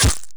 strike1.wav